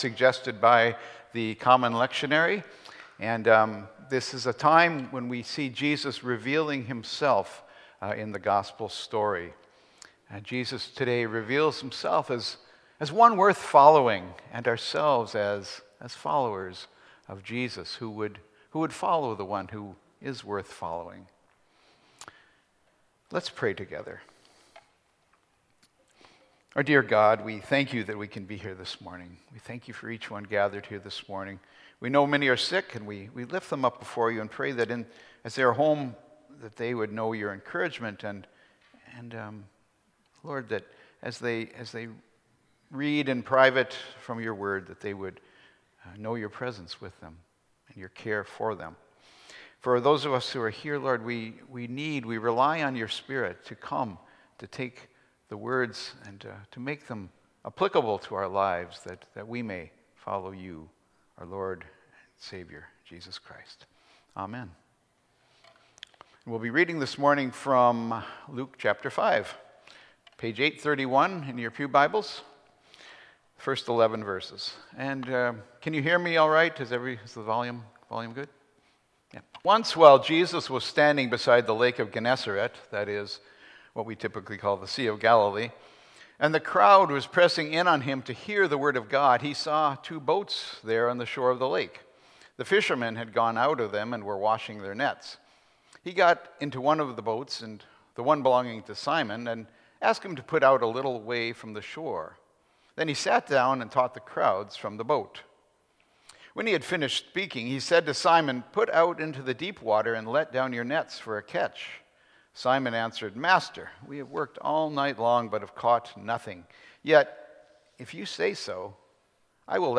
Sermons | Fellowship Christian Reformed Church of Toronto